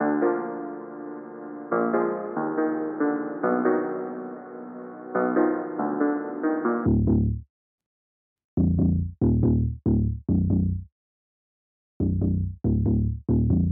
阿维德型钢琴和低音提琴
Tag: 140 bpm Trap Loops Piano Loops 2.31 MB wav Key : D FL Studio